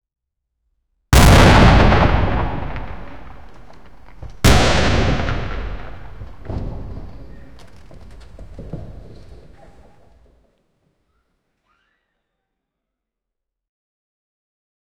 Buildings Sound Effects - Free AI Generator & Downloads
un-btiment-de-12-tage-kq64rw3j.wav